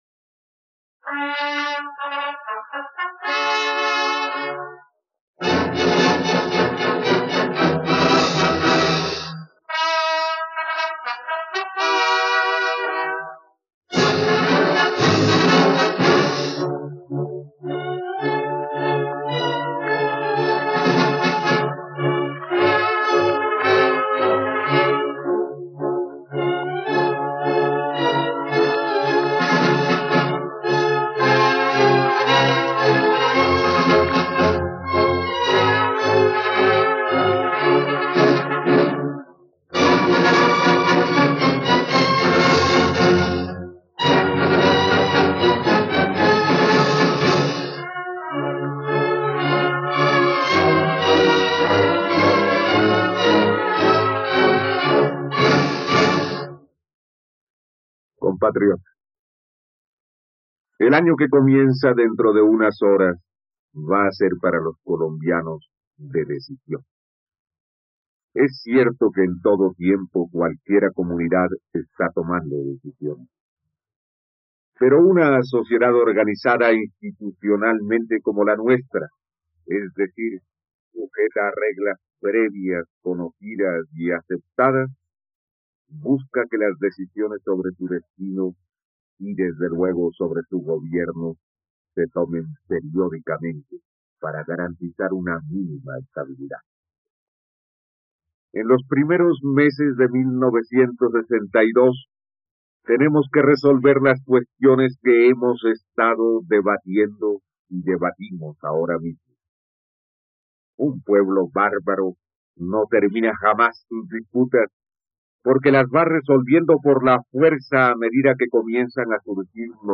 Discurso de Año Nuevo Alberto Lleras Camargo | RTVCPlay